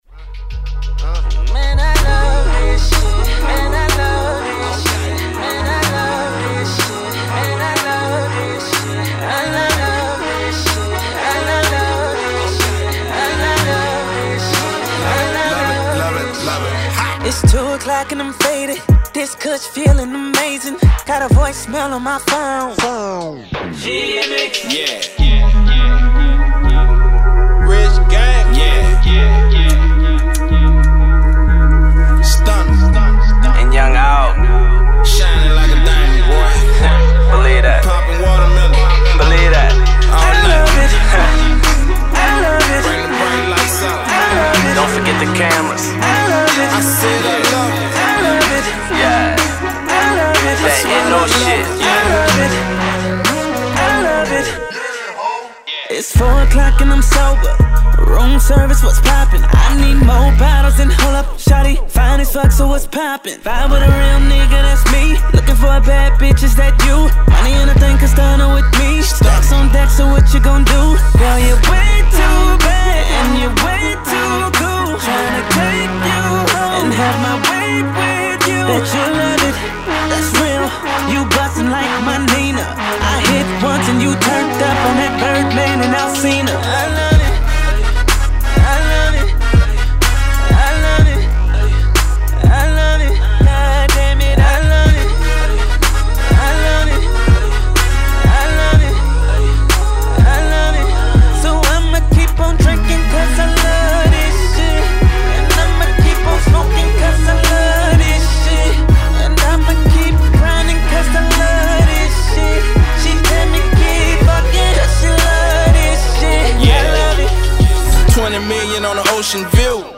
alters his single with new vocals